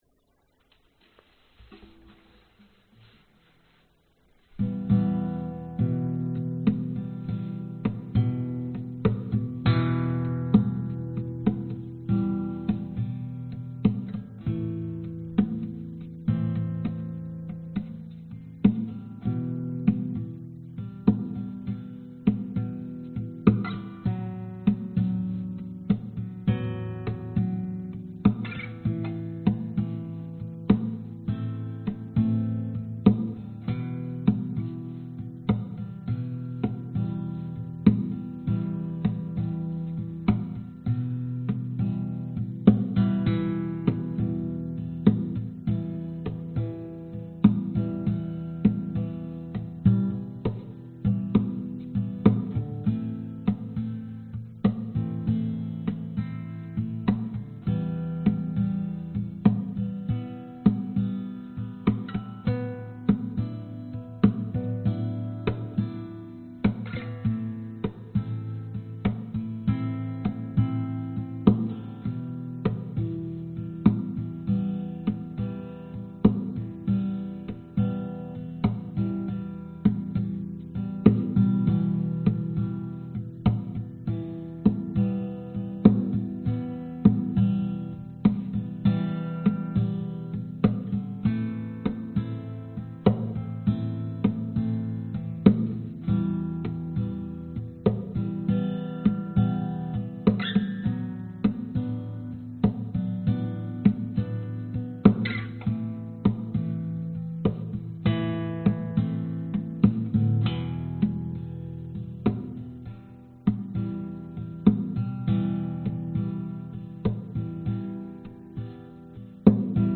描述：我用大拇指敲击和弦，用手指在音板上打拍子，演奏了一首原声GTR。
Tag: 原声 寒冷 吉他